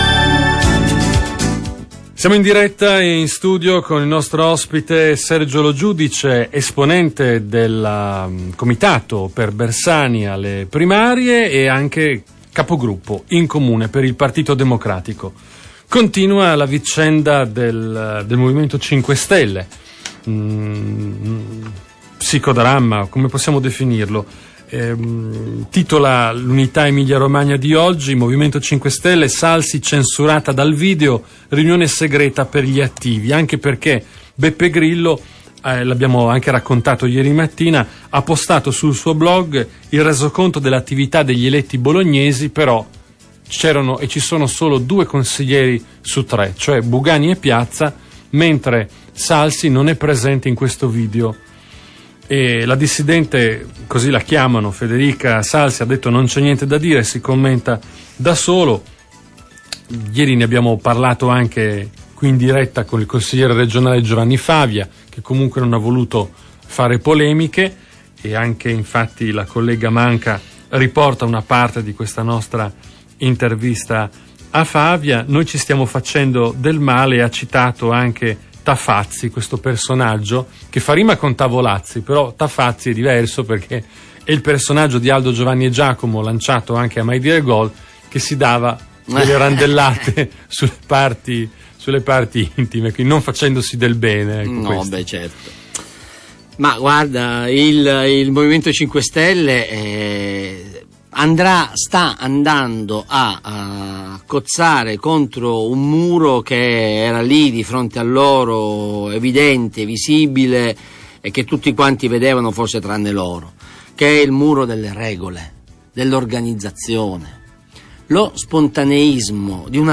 Intervento del presidente del gruppo consiliare Pd, Sergio Lo Giudice, a Radio Tau.